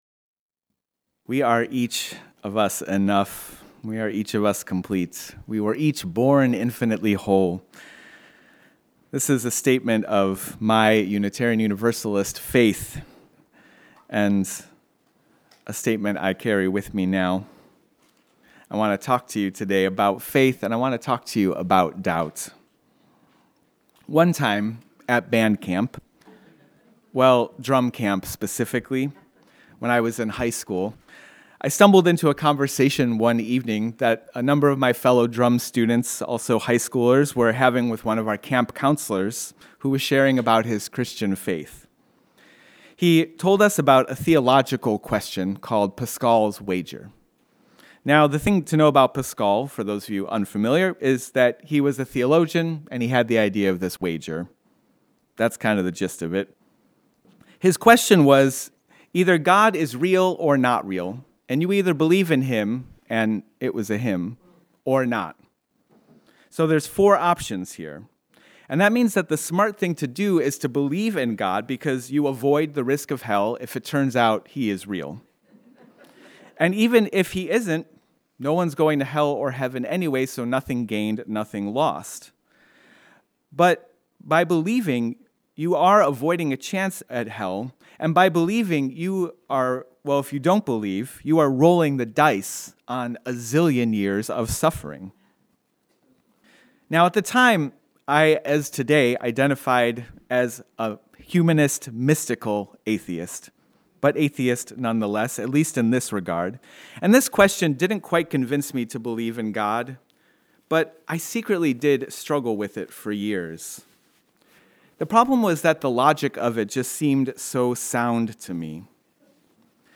Join us for a celebration of faith and doubt through story and song.